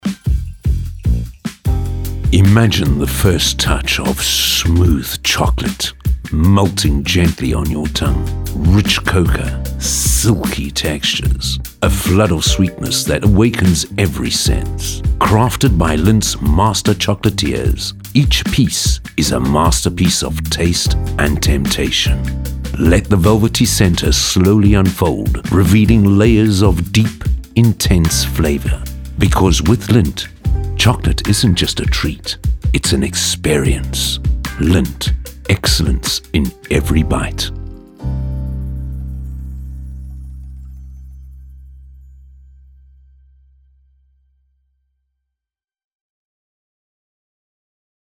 articulate, authentic, captivating, confident, Deep, energetic, friendly
30-45, 45 - Above
Appetite appeal